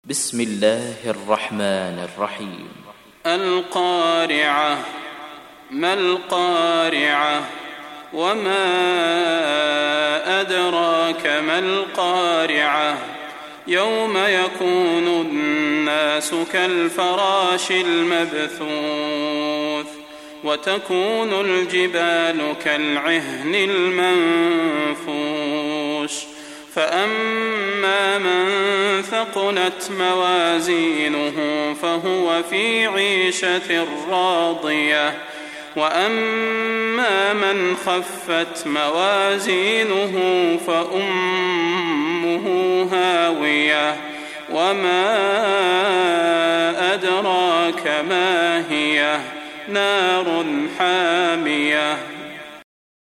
دانلود سوره القارعه mp3 صلاح البدير روایت حفص از عاصم, قرآن را دانلود کنید و گوش کن mp3 ، لینک مستقیم کامل